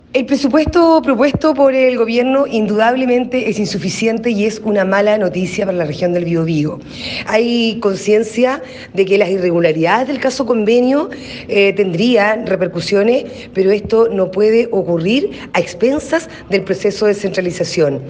Por su parte, la diputada cercana a la UDI, Marlene Pérez, también consideró que la asignación de dineros es insuficiente y que pasó la cuenta el caso Fundaciones.